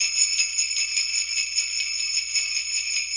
Sleigh Bell.wav